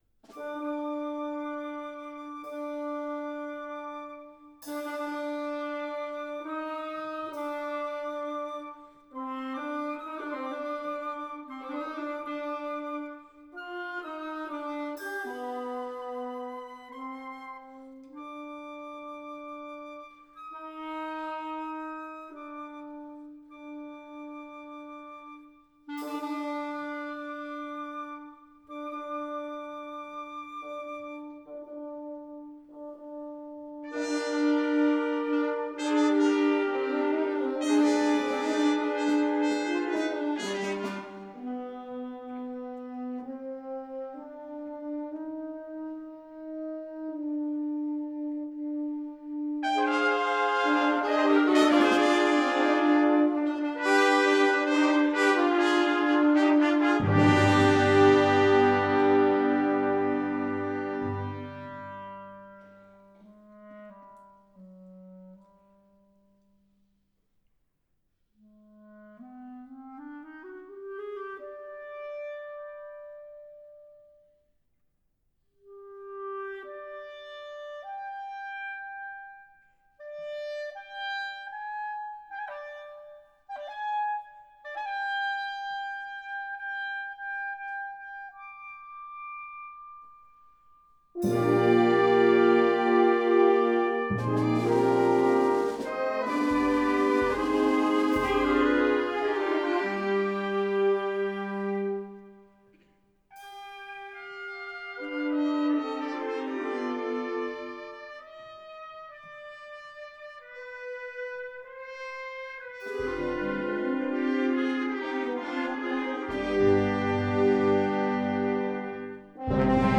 für Harmonie